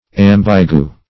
Search Result for " ambigu" : The Collaborative International Dictionary of English v.0.48: Ambigu \Am"bi*gu\, n. [F., fr. ambigu doubtful, L. ambiquus.